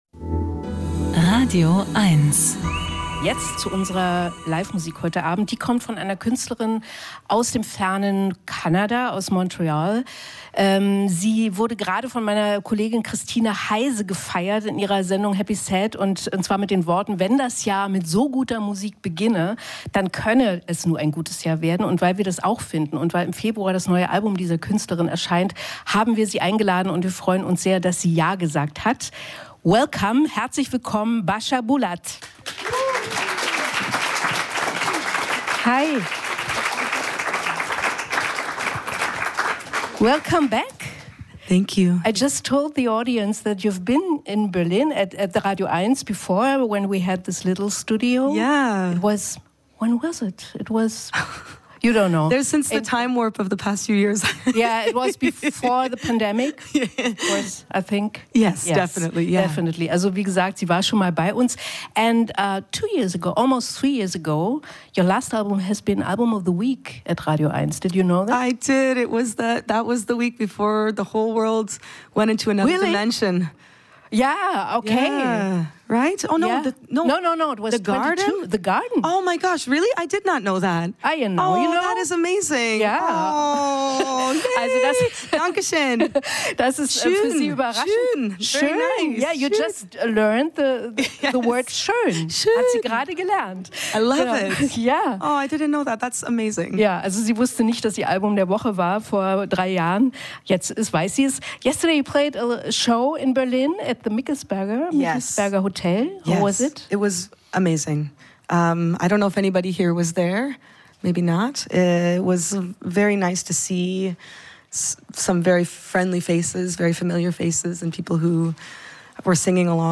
Musik-Interviews
Die besten Musikerinnen und Musiker im Studio oder am Telefon gibt es hier als Podcast zum Nachhören.